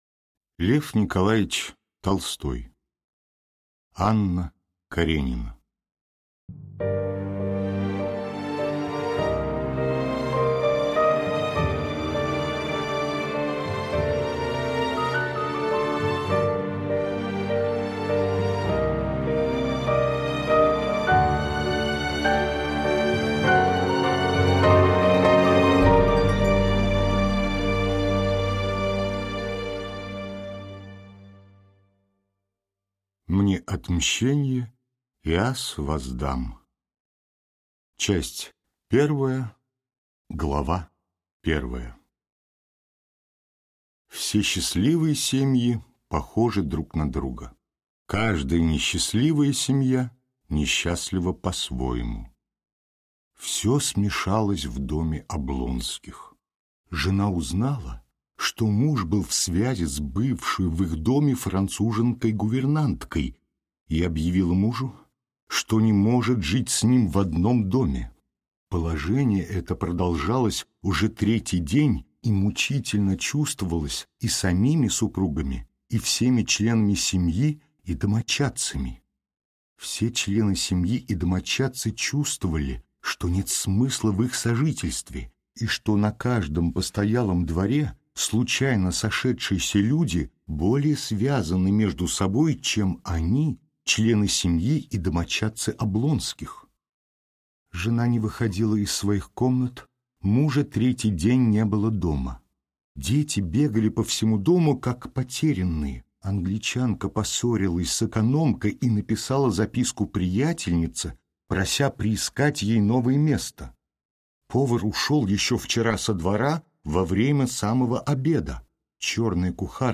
Аудиокнига Анна Каренина | Библиотека аудиокниг